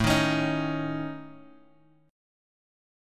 AMb5 chord {x x 7 6 4 5} chord
A-Major Flat 5th-A-5,6,x,6,4,x.m4a